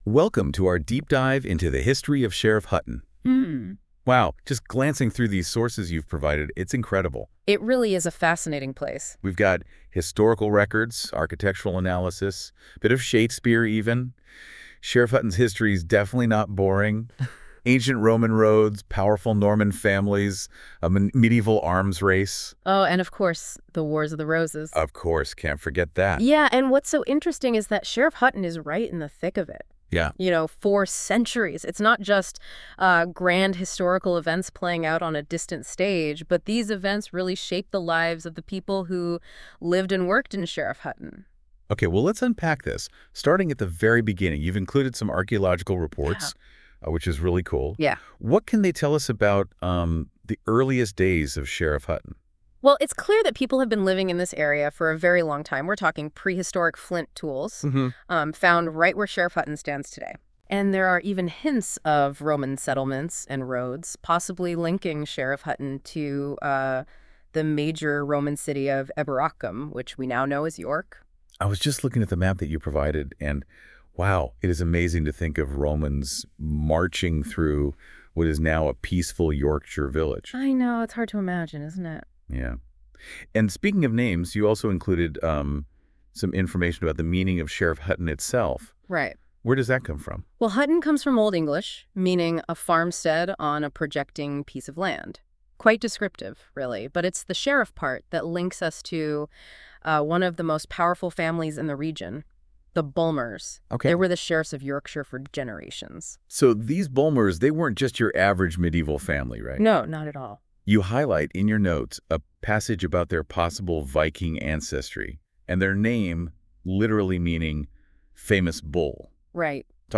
Using Google�s Notebook LM, listen to an AI powered podcast summarising this page.